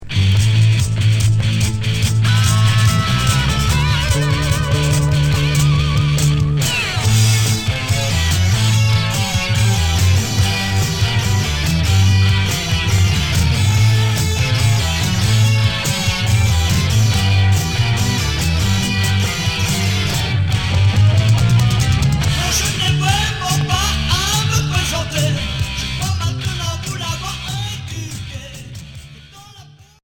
Glam Deuxième 45t retour à l'accueil